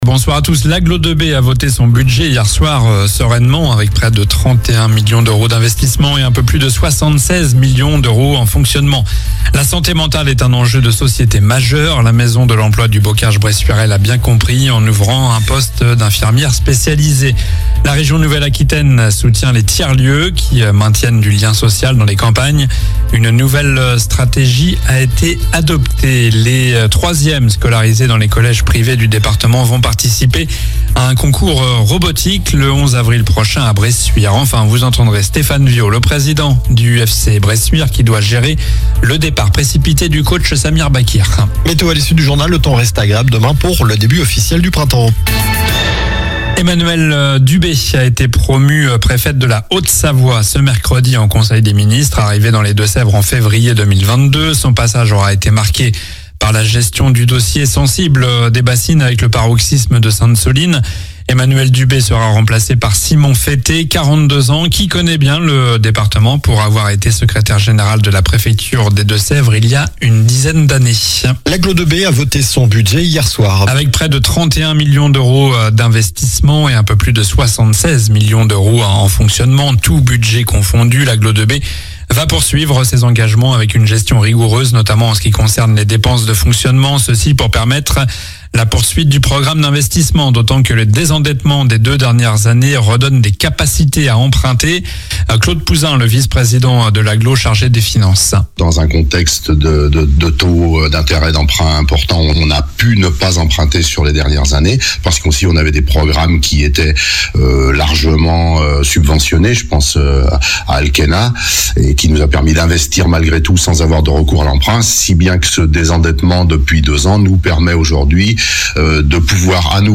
Journal du mercredi 19 mars (soir)